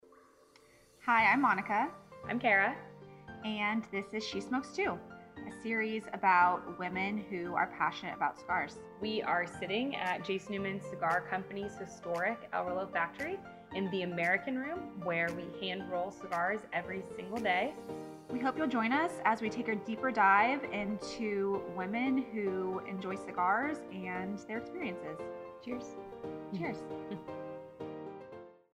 interview women in and out of the industry who love cigars